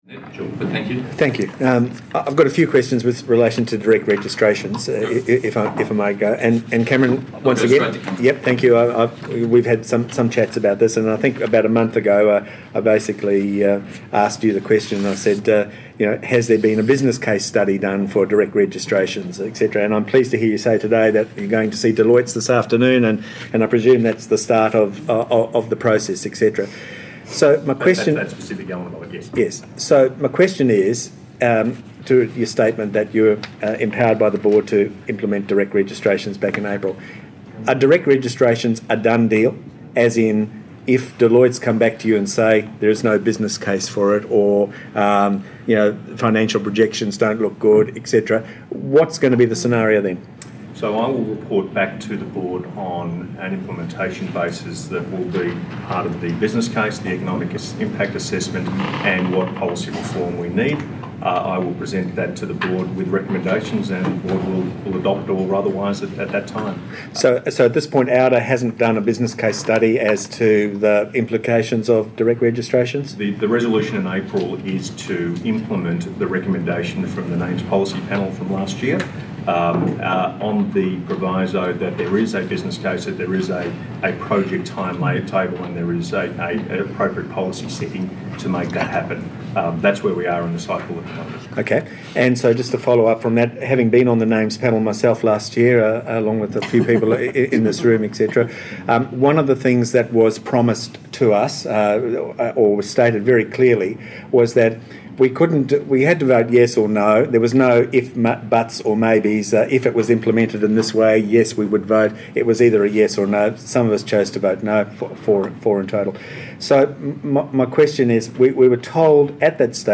Apologies in advance for the quality – it was done from a distance.